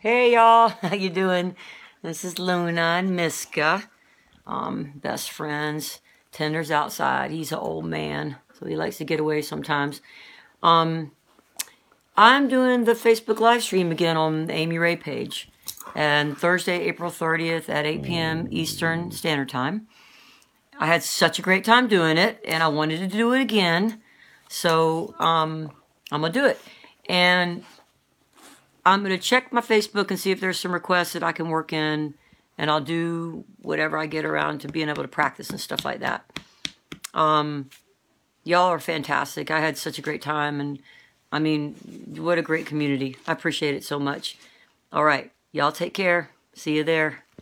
(captured from the facebook live video stream)
01. promo (0:47)